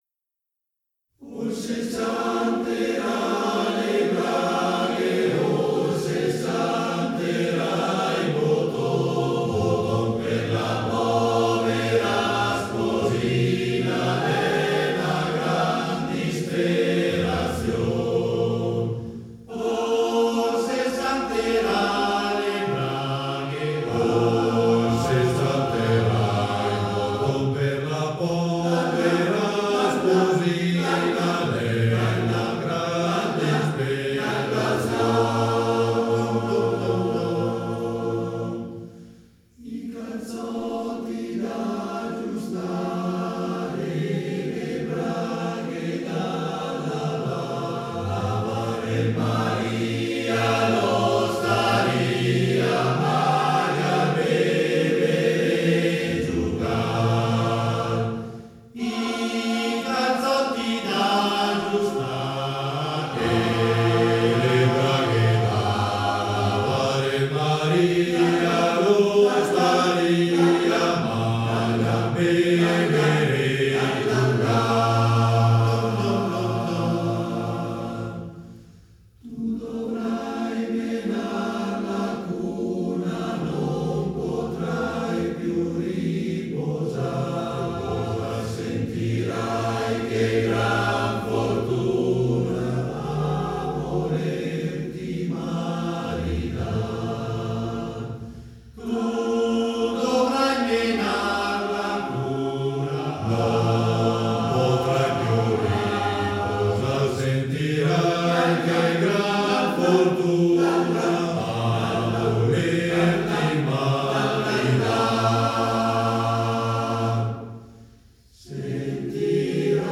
Arrangiatore: Vettorazzi, Cecilia
Esecutore: Coro Valle dei Laghi